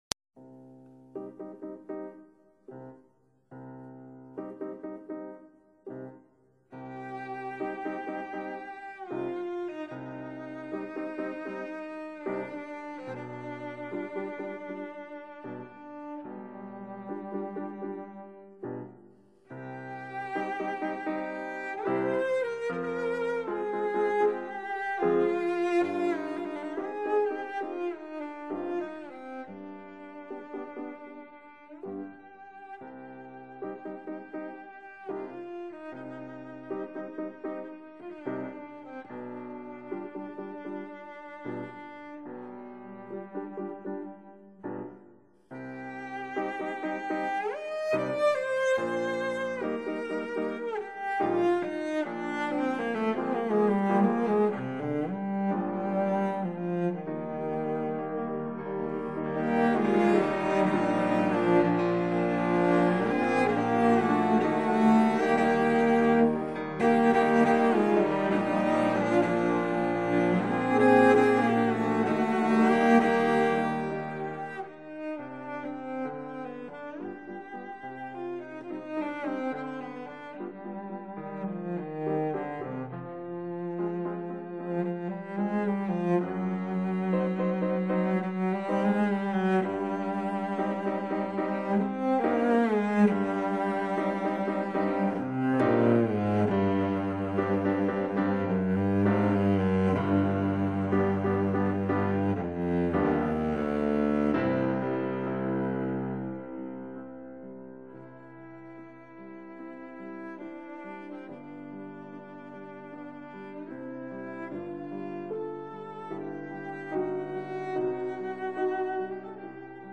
Cello
Piano
"자클린의 눈물"처럼 선율이 매우 아름답고 애절해 가슴을 쓰리게 하는 작품인데, 슬픔보다 오히려 고요한 평화를 느낄 수도 있습니다.